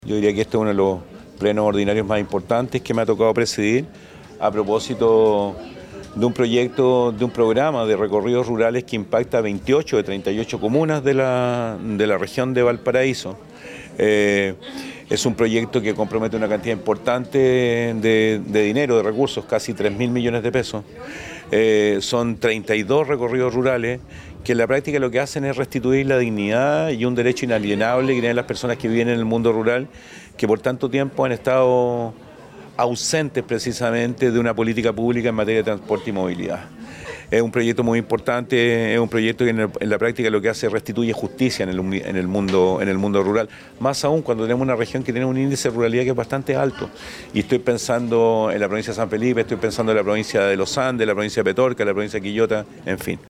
El Gobernador Regional de Valparaíso, Rodrigo Mundaca, calificó lo aprobado como un hecho «inédito».